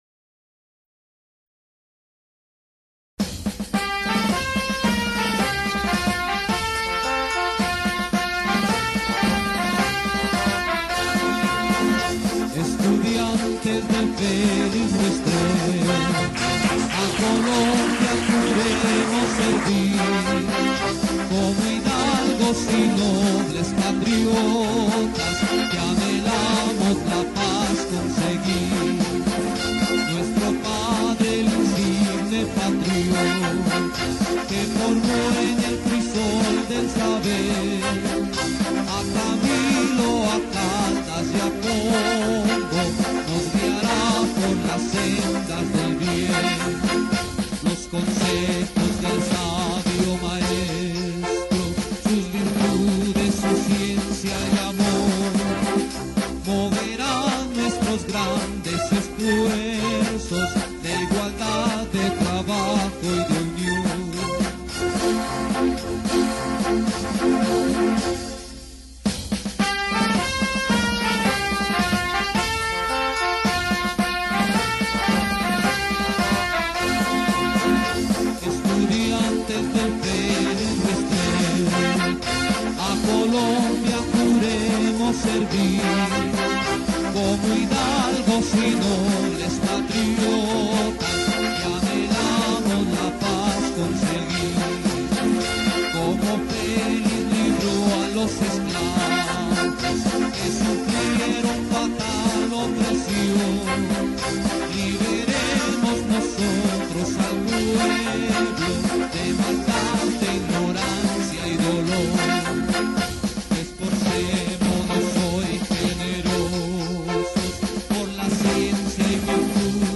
Himno-Colegio-Tecnico-Jose-Felix-Restrepo-IED.mp3